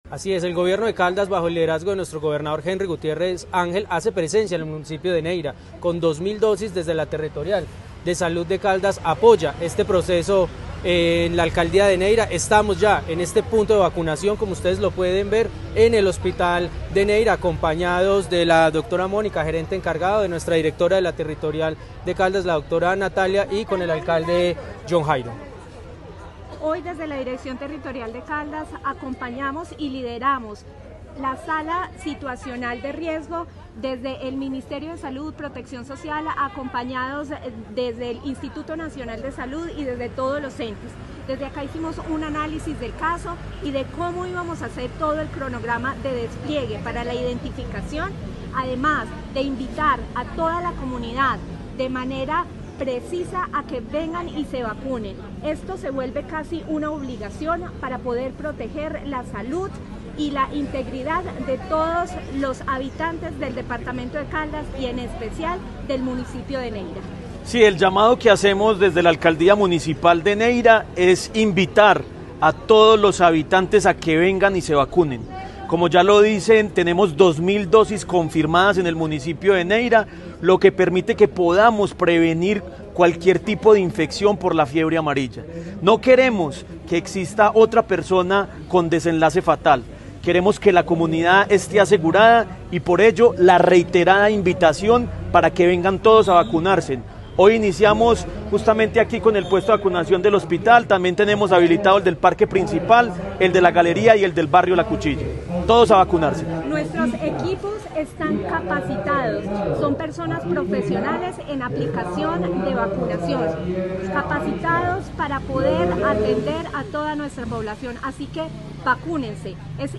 Ronald Fabián Bonilla Ricardo, gobernador (e) de Caldas, Natalia Castaño Díaz, directora de la DTSC y Jhon Jairo Castaño Flórez, alcalde de Neira